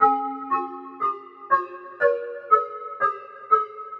特里普特长笛
Tag: 120 bpm Trap Loops Flute Loops 689.21 KB wav Key : Unknown FL Studio